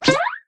poco_new_reload_02.ogg